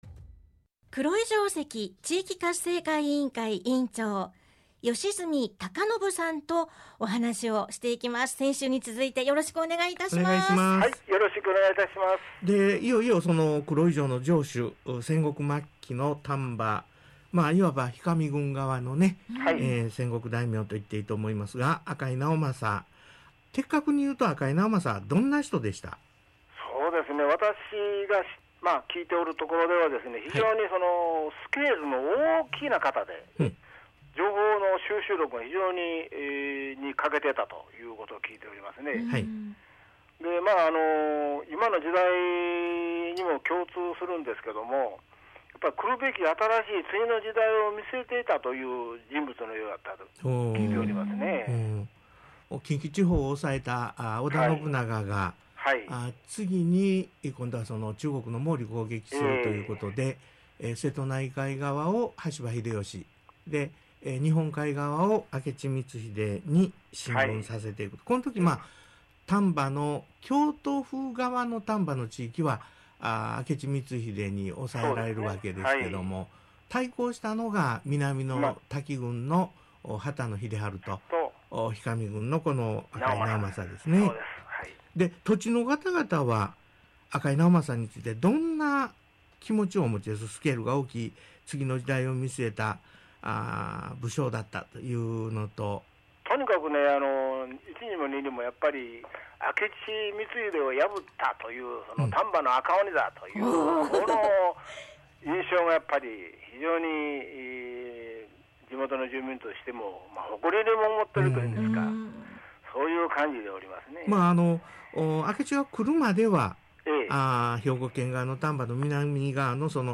『ラジオで辿る光秀ゆかりの兵庫丹波』2020年9⽉26⽇放送回音声